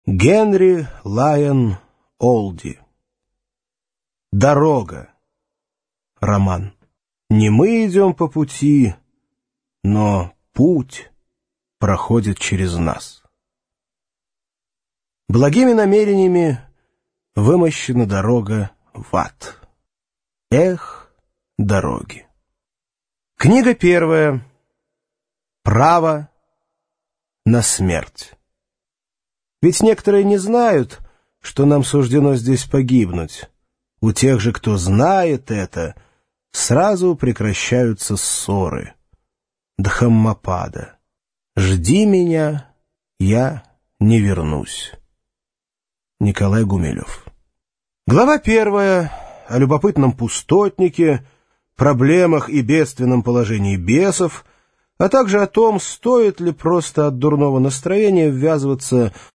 Аудиокнига Дорога | Библиотека аудиокниг
Прослушать и бесплатно скачать фрагмент аудиокниги